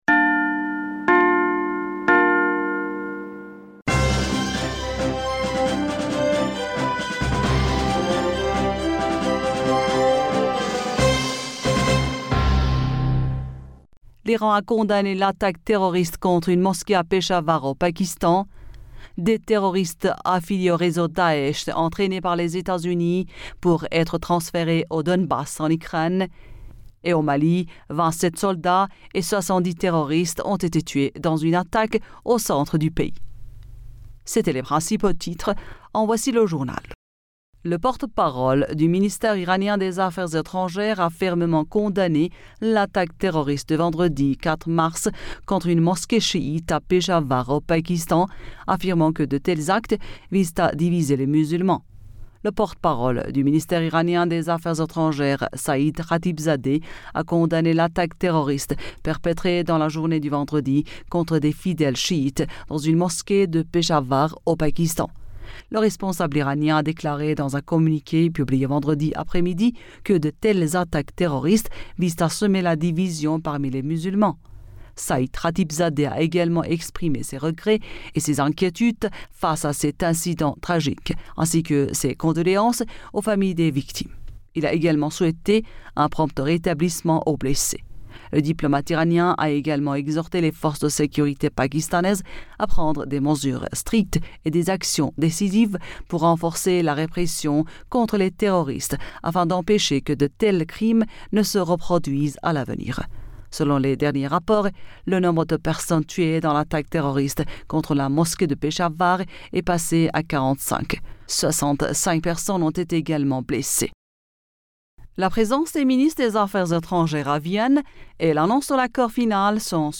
Bulletin d'information Du 05 Mars 2022